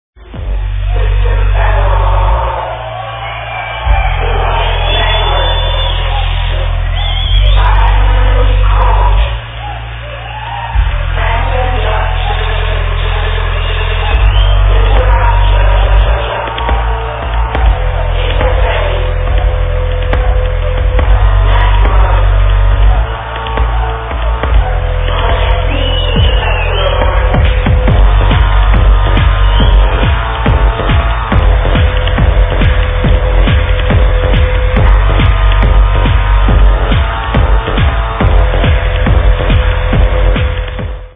the vocals go sumthing along the lines of this: